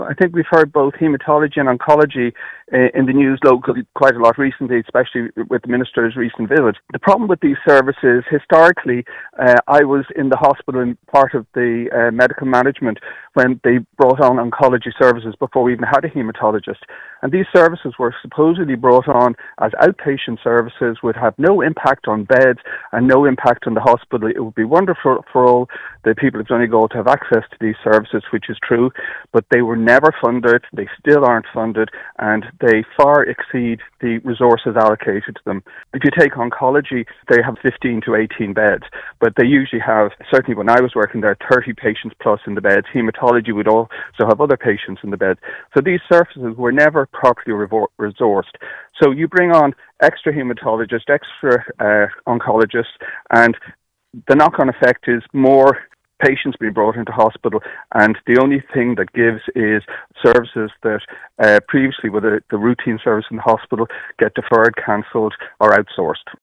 He told the Nine til Noon Show this morning that even though Sligo has a catchment of 84,000, it has historically received more money than Letterkenny, with a catchment of 120,000.